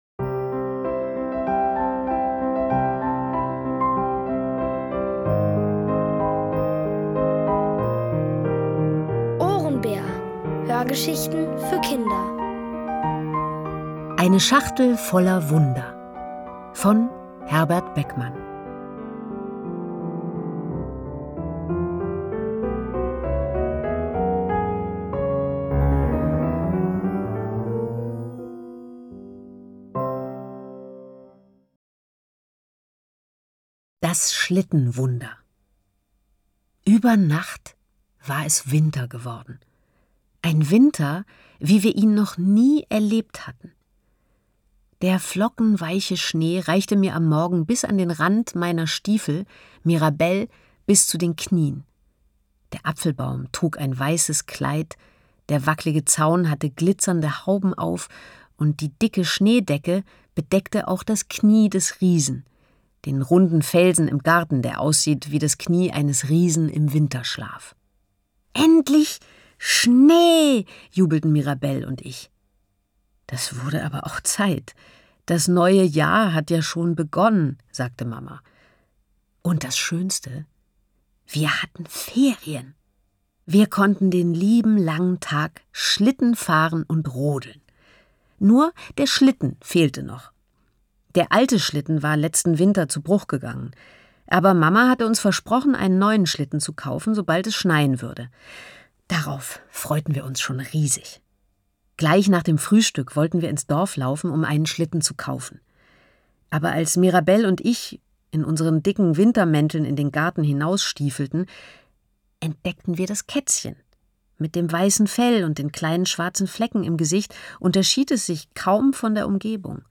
Von Autoren extra für die Reihe geschrieben und von bekannten Schauspielern gelesen.
Es liest: Nina Hoss.